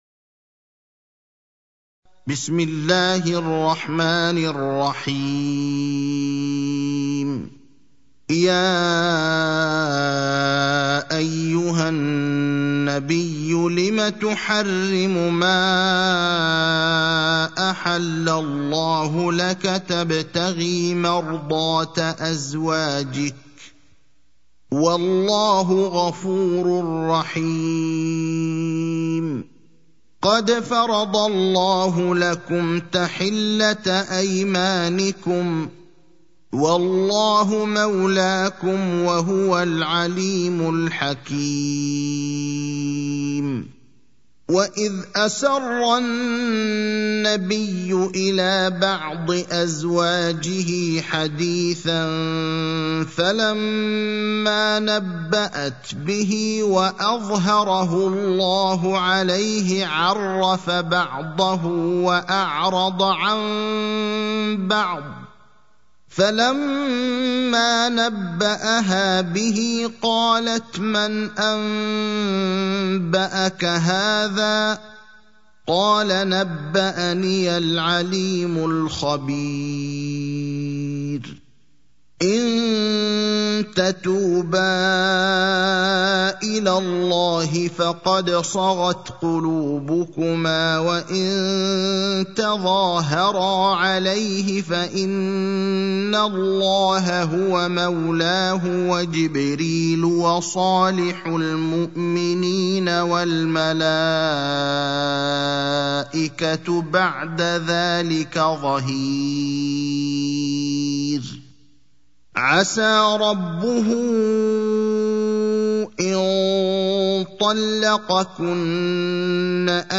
المكان: المسجد النبوي الشيخ: فضيلة الشيخ إبراهيم الأخضر فضيلة الشيخ إبراهيم الأخضر التحريم (66)